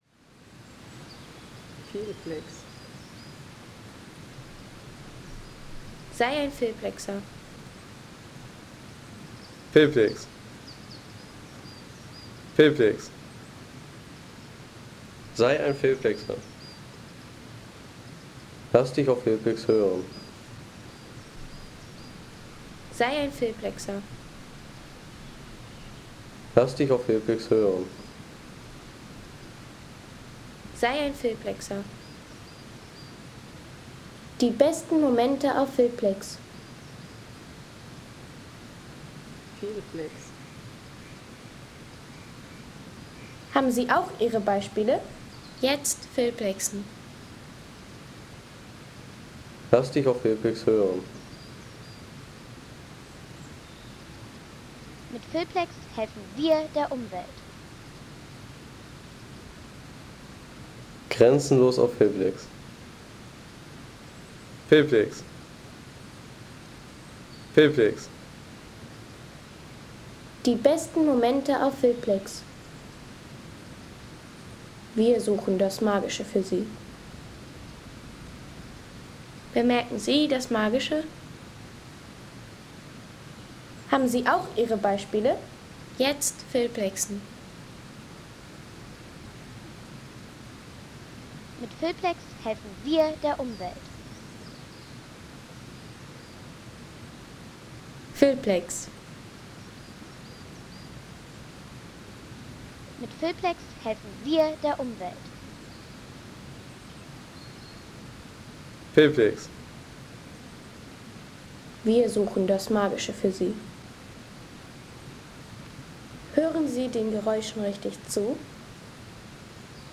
Geoweg Hermannstal Waldsound | Feelplex
Ruhige Waldatmosphäre vom Geoweg Hermannstal in Seefeld mit sanftem Bachrauschen.
Natürliche Atmosphäre vom Geoweg Hermannstal mit ruhigem Wald, sanftem Hagelbach und dem Charakter eines geologischen Themenwegs bei Seefeld.